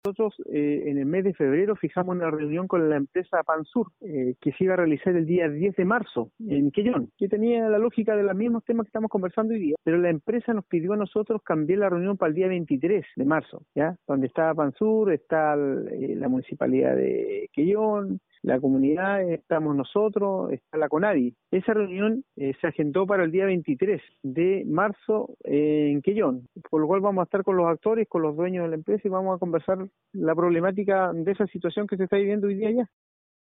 El titular del gobierno en la provincia manifestó que esta mesa de trabajo, de concretarse, será de mucha utilidad para poder ir resolviendo los temas que mantienen distanciados a los comuneros y a la empresa en cuestión.